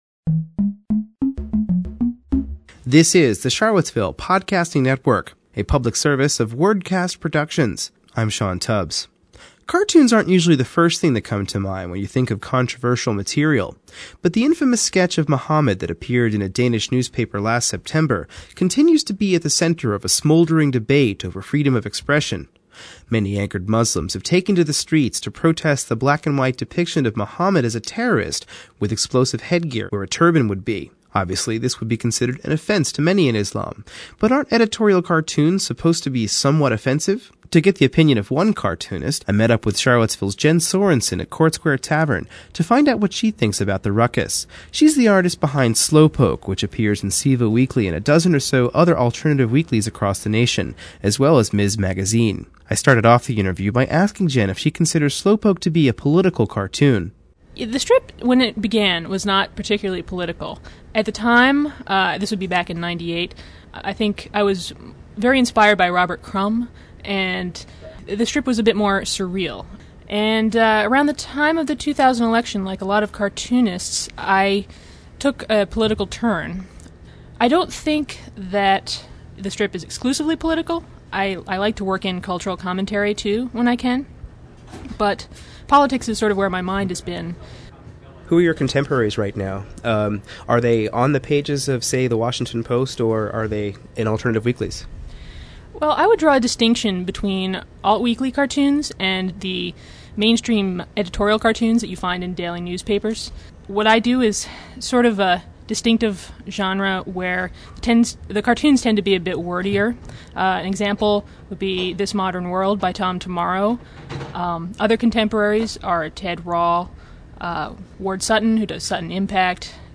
spoke with Orr in his office last month for a conversation about his career, the difference between poetry and prose, and about the time he spent in Mississippi forty years ago this summer.